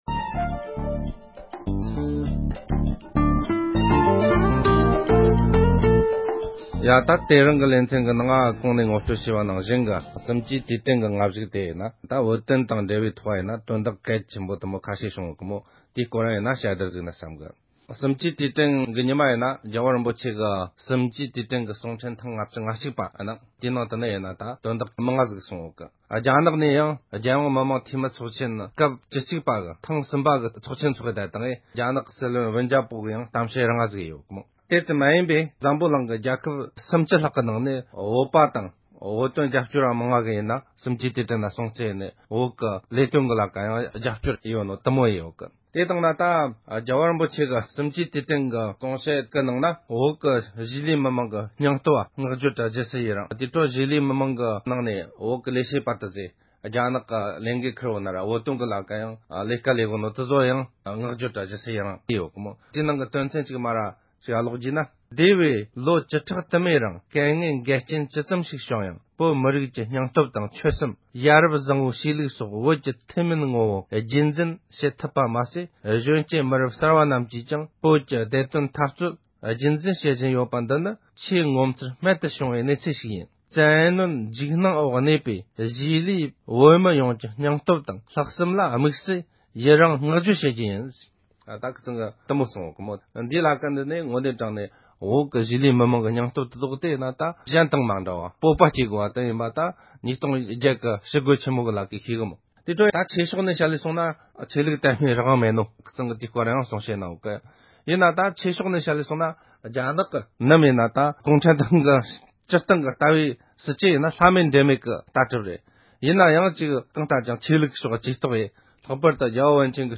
བོད་དོན་དང་འབྲེལ་བའི་དཔྱད་གཏམ།